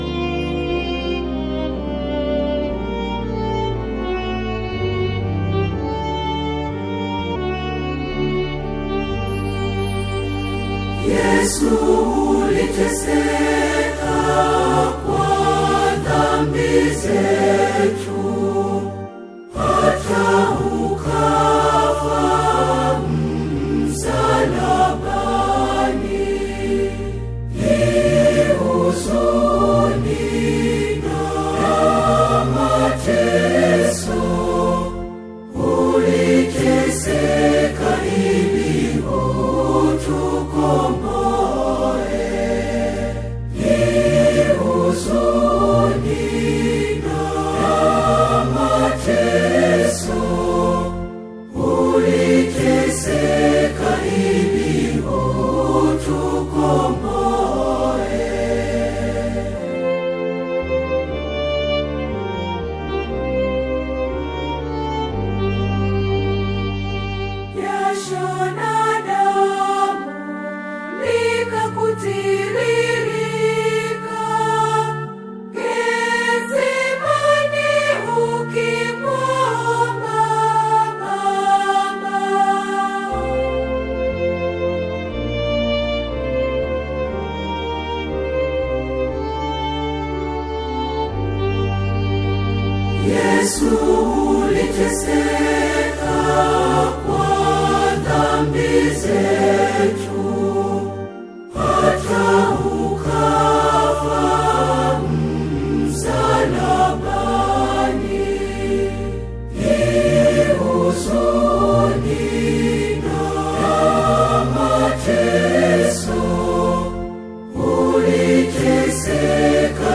The majestic and deeply evocative Lenten hymn